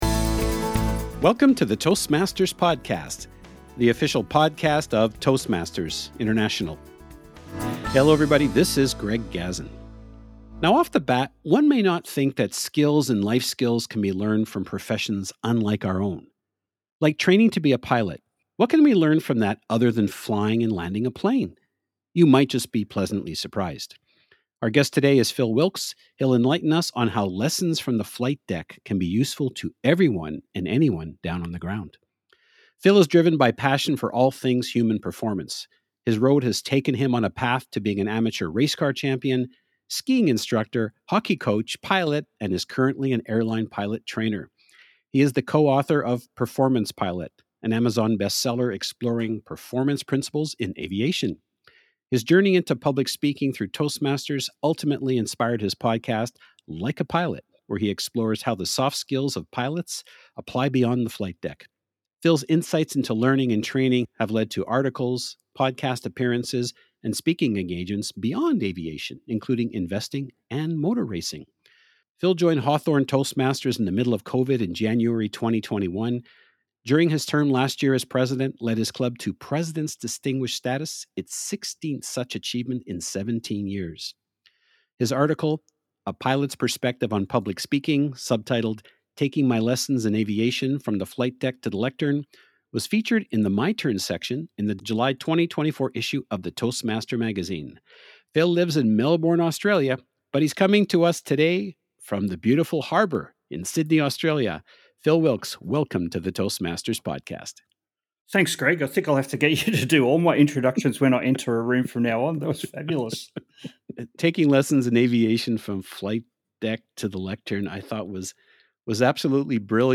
The Toastmasters Podcast is like a radio talk show featuring interviews with Toastmasters usually featured in the Toastmaster magazine.